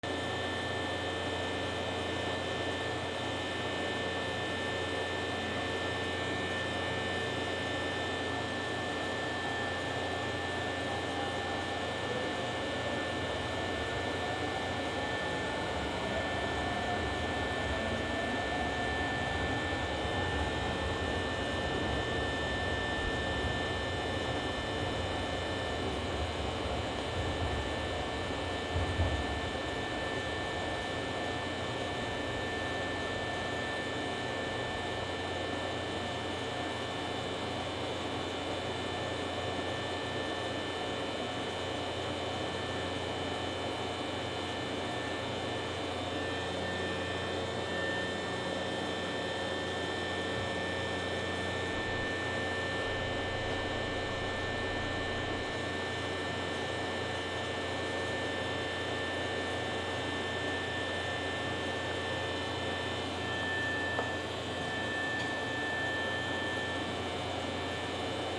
電車停車中
/ E｜乗り物 / E-60 ｜電車・駅
終電 D50